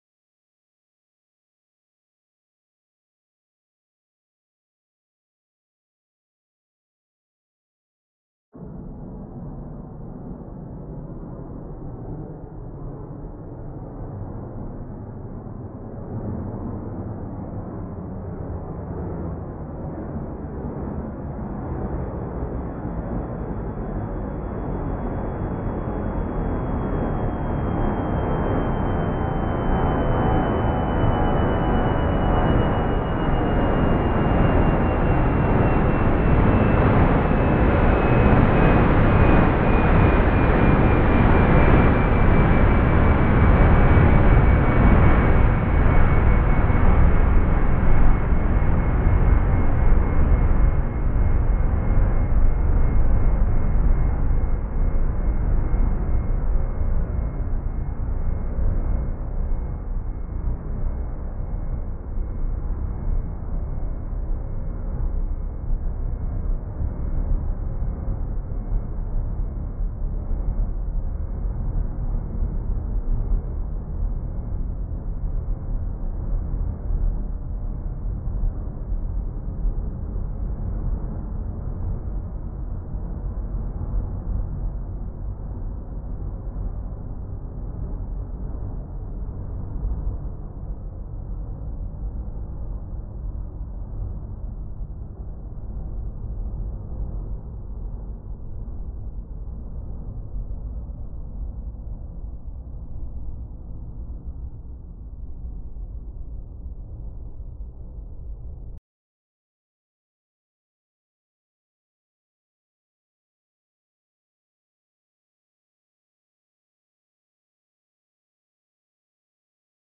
Auralization of atmospheric turbulence-induced amplitude fluctuations in aircraft flyover sound based on a semi-empirical model | Acta Acustica
03_synthesis_low_turbulence.mp3 (8
)   Synthesis Condition I